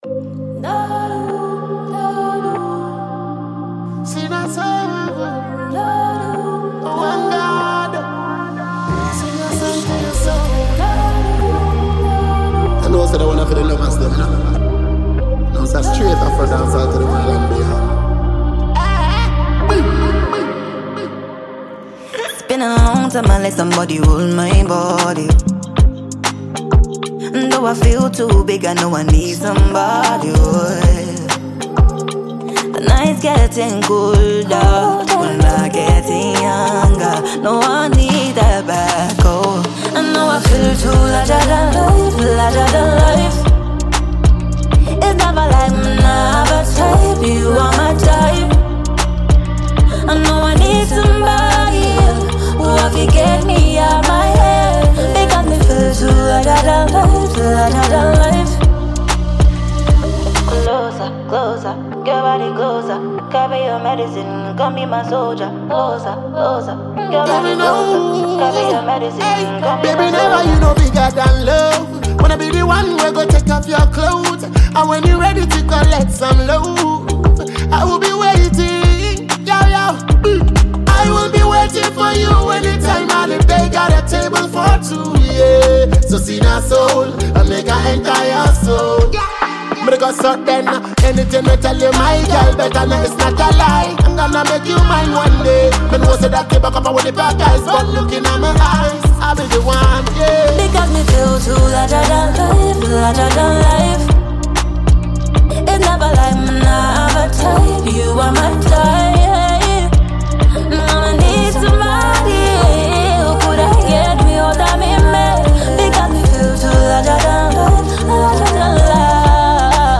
Ghanaian female vocalist